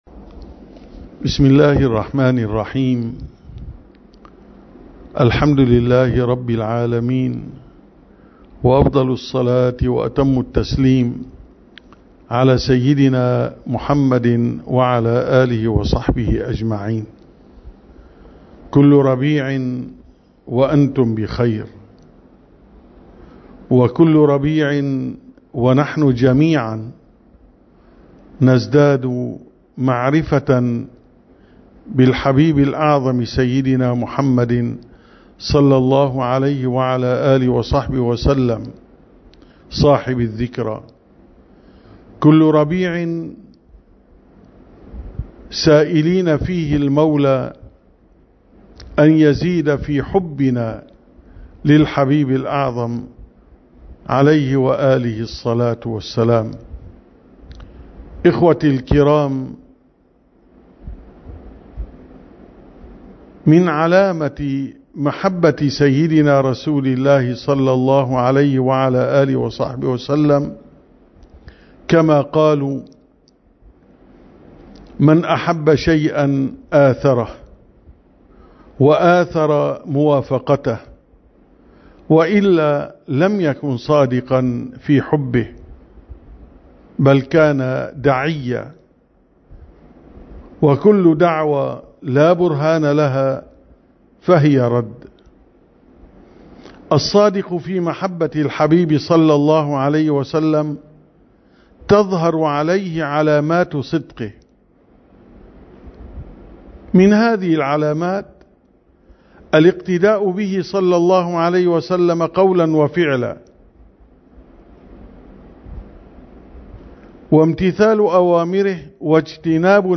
دروس صوتية